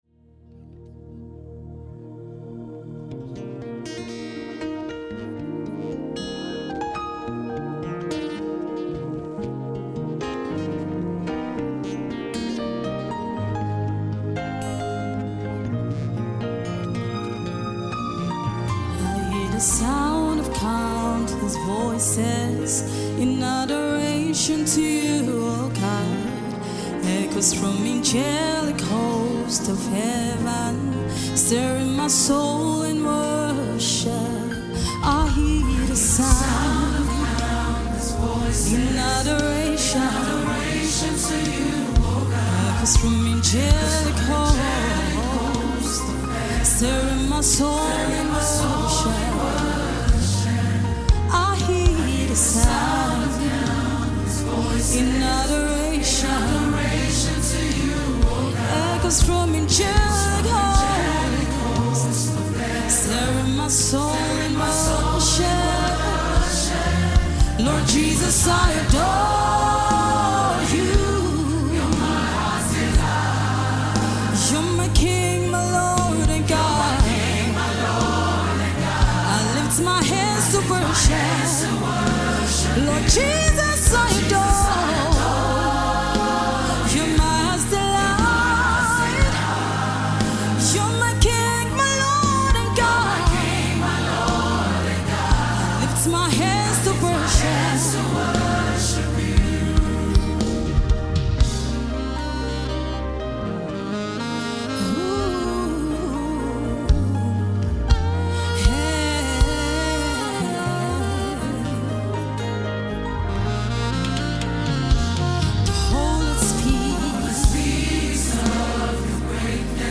Lyrics, Praise and Worship